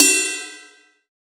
• Long Room Reverb Crash Cymbal Single Hit C# Key 04.wav
Royality free crash cymbal drum sound tuned to the C# note. Loudest frequency: 6072Hz
long-room-reverb-crash-cymbal-single-hit-c-sharp-key-04-2yS.wav